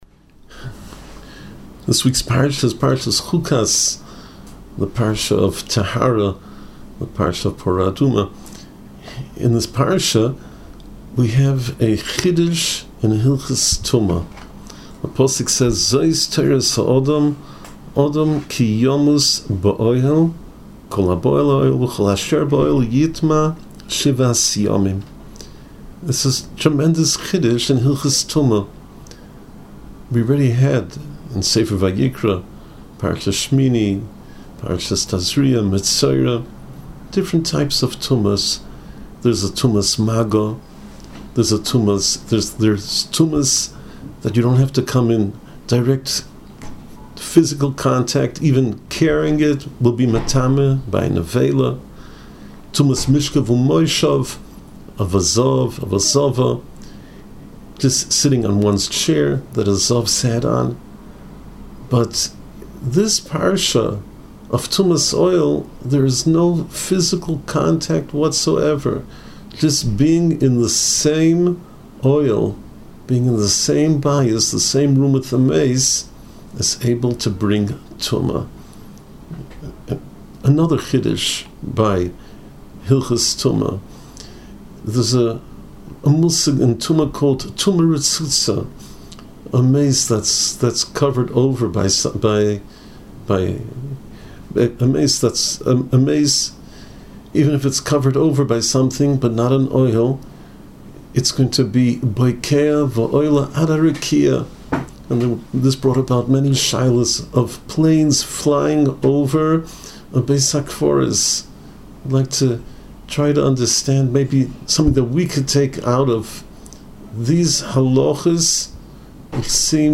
Inspiring Divrei Torah, Shiurim and halacha on Parshas Chukas from the past and present Rebbeim of Yeshivas Mir Yerushalayim.
Parsha Preview Audio